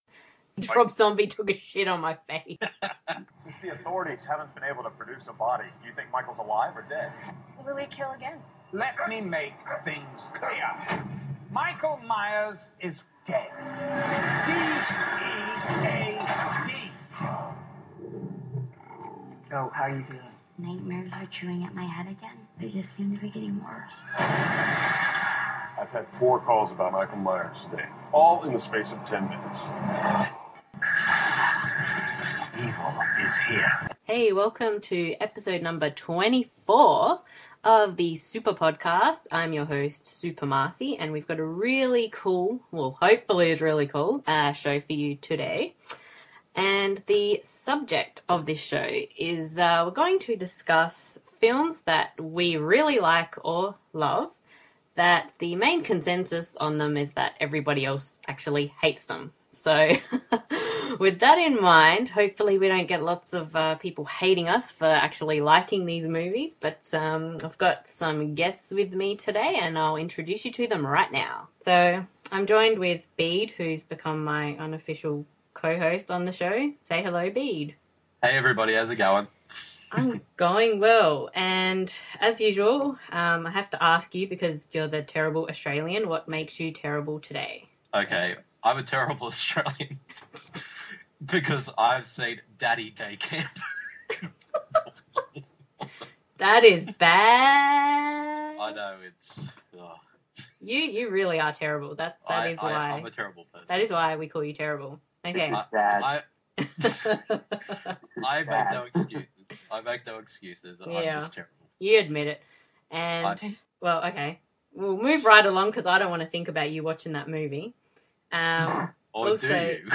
One of my picks reveals my Kurt Russell obsession, which leads to some bad impersonations.